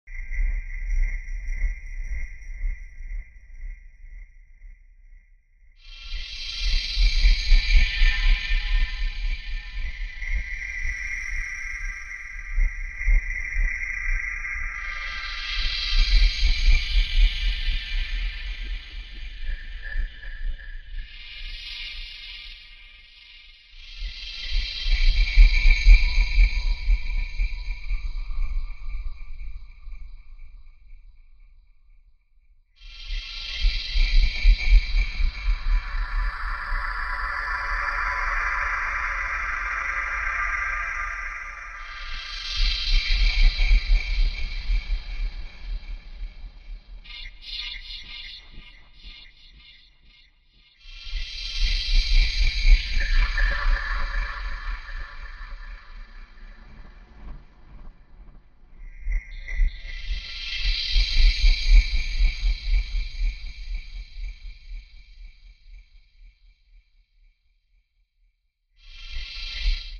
Forest at Night heavy shuttering with eerie whispers Heavy Sub Energy